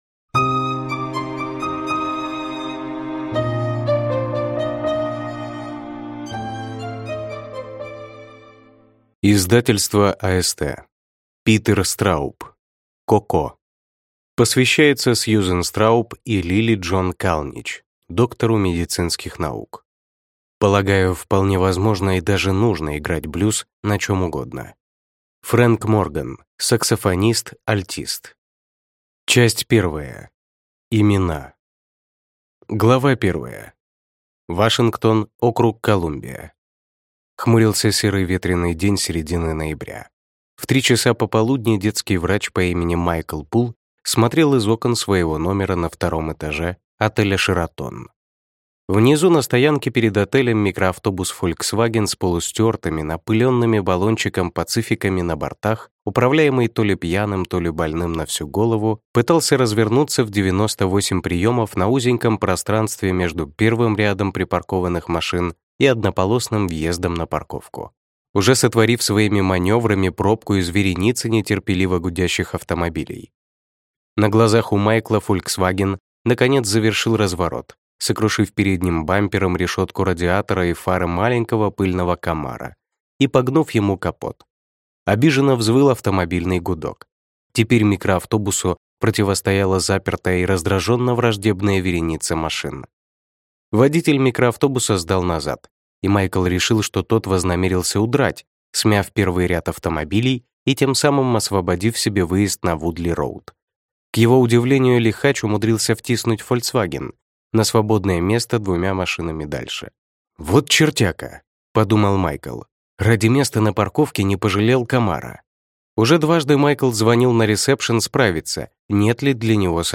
Аудиокнига Коко | Библиотека аудиокниг